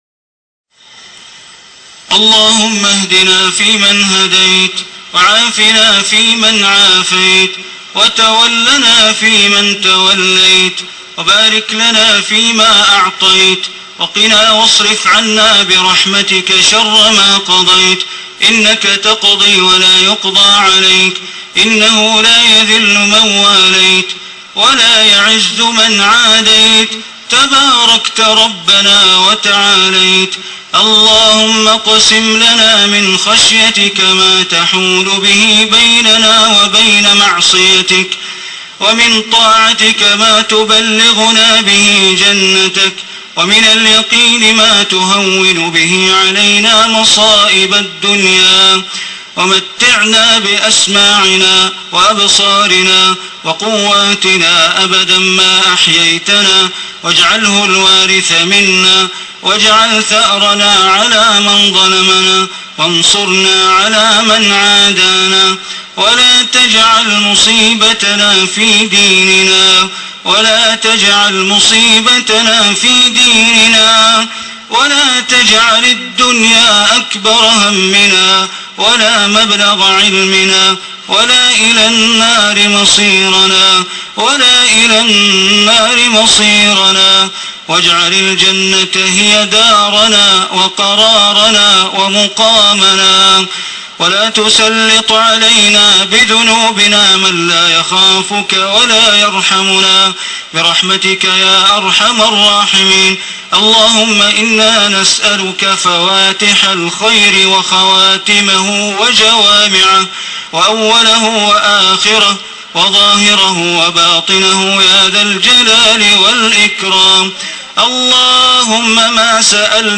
دعاء خاشع للشيخ بندر بليلة من الحرم المكي ليلة 16 رمضان 1434هـ.
تسجيل لدعاء خاشع للشيخ بندر بليلة في الحرم المكي ليلة 16 رمضان 1434هـ.